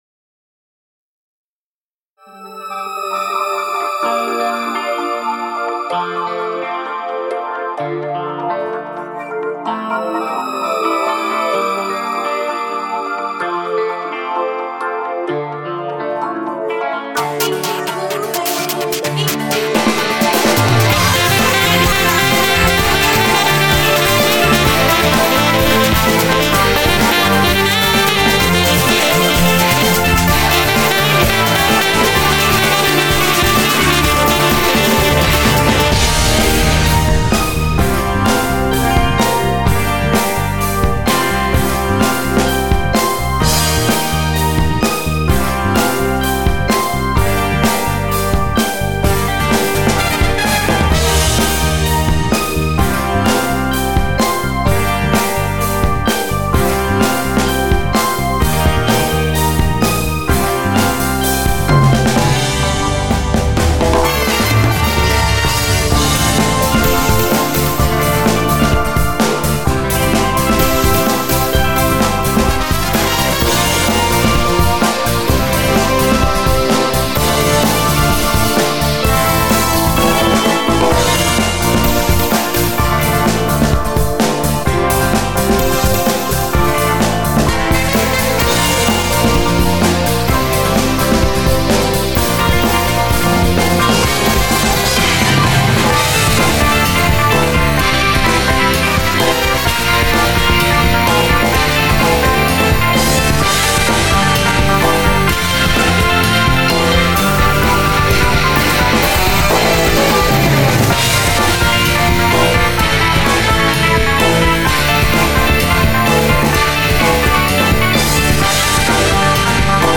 Cubaseにて修正＆再ミックス。
前半メロディ音色変更
Ver2.0のアレンジは前作よりテンポを上げて歌モノっぽい感じを意識して作成してみました。